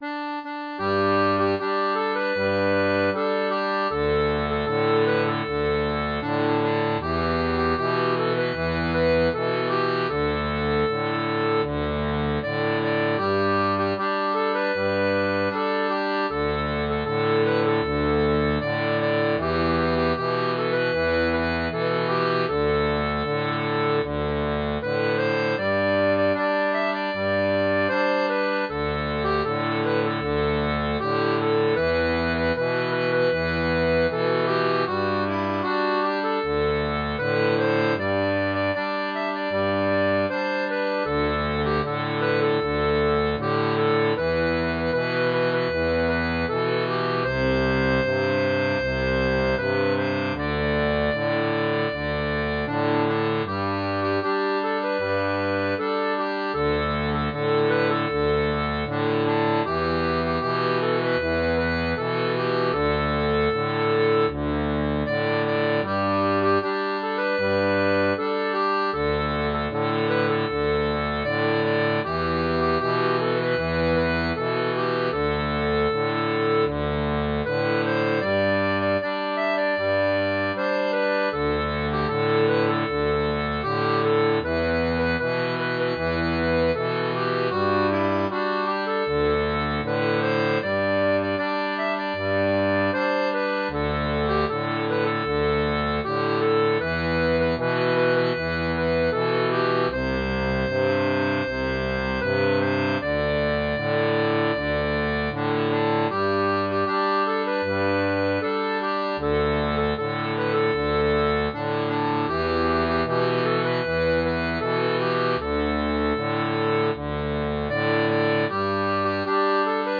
Type d'accordéon
Folk et Traditionnel